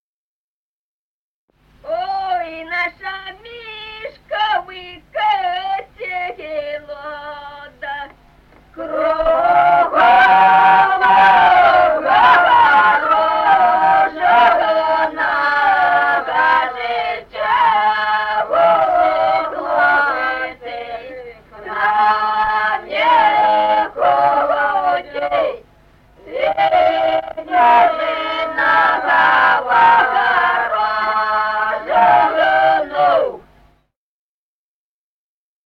Музыкальный фольклор села Мишковка «Ой, наша Мишковка-село», припевки.